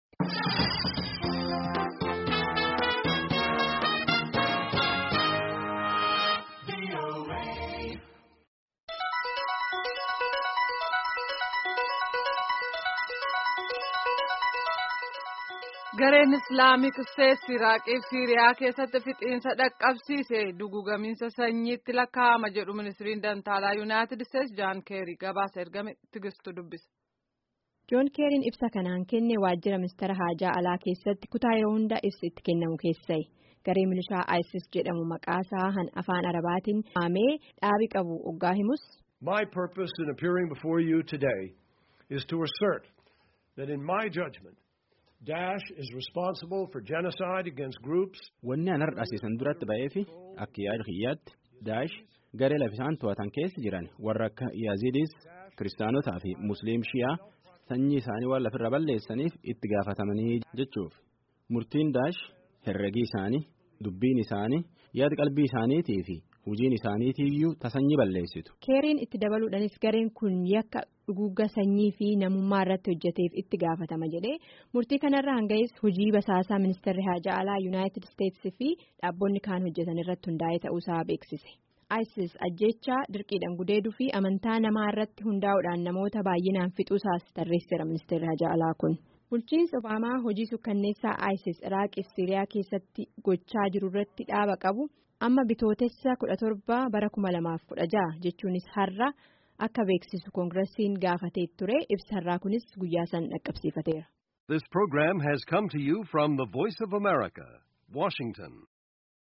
Gabaasni sagalee kunooti.